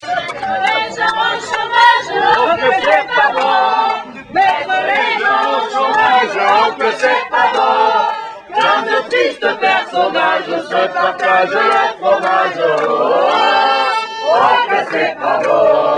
Nous troublons pendant une heure la digestion de ces honnêtes gens, après quoi, nous quittons cette sympathique soirée, quoique nous regrettions un peu le manque d'ambiance, car lorsque nous avons chanté :
(Sur l'air de : en passant par la Lorraine avec tes sabots)